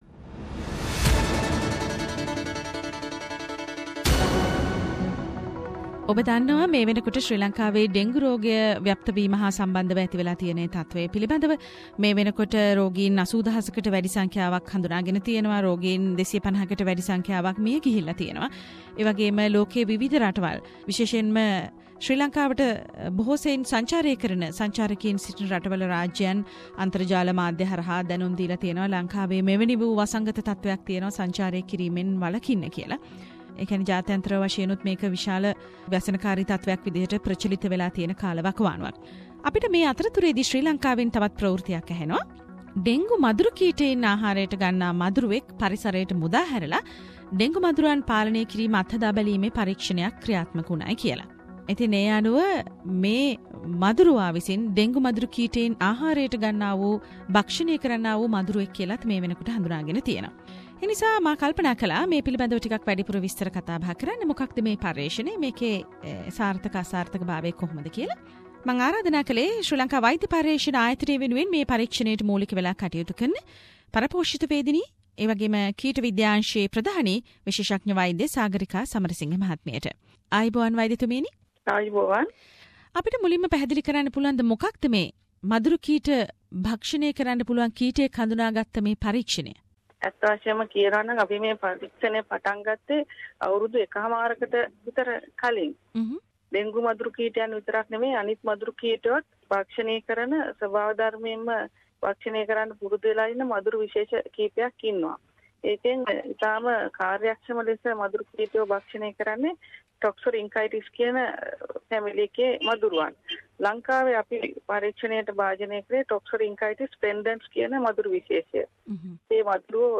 සාකච්චාවක්